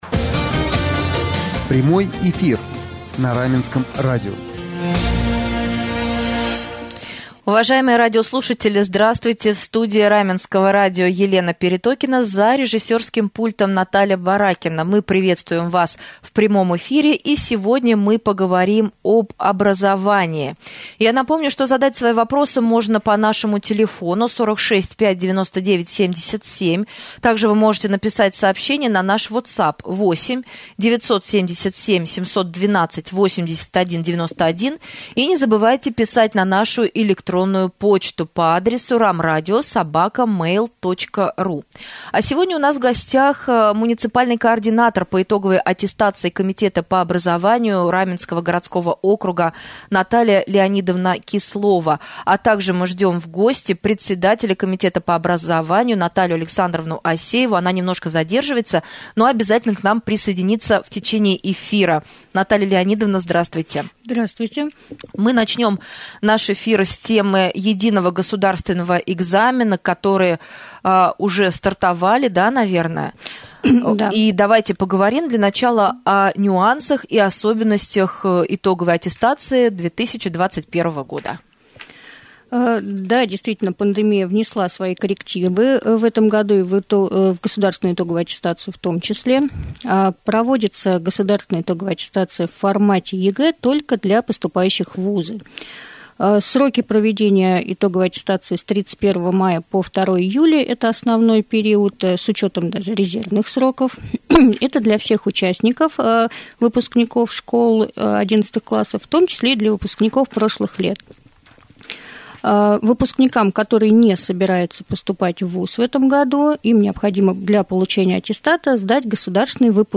Горячая пора наступила в школах Раменского городского округа. О том как прошел праздник «Последнего звонка», о нюансах ЕГЭ и ОГЭ 2021 года, о предстоящих выпускных вечерах и о летней оздоровительной компании, в прямом эфире Раменского радио мы поговорили с председателем Комитета по образованию Раменского г.о.